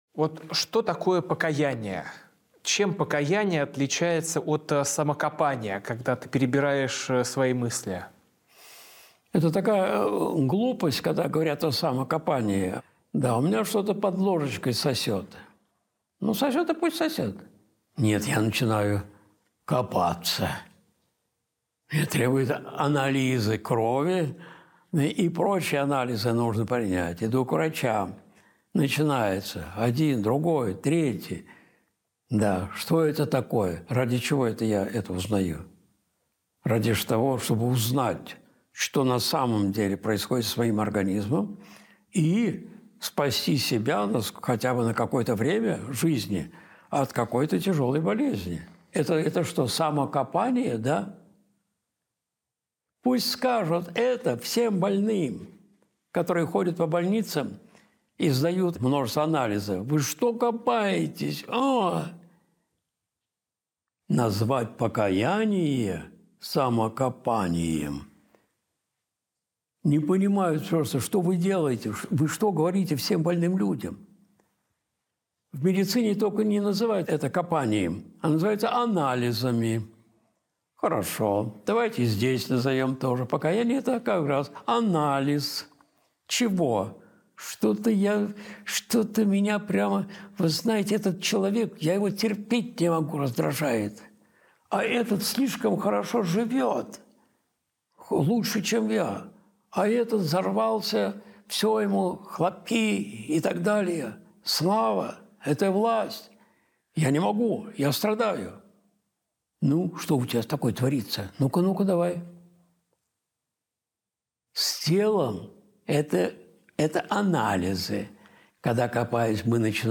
Видеолекции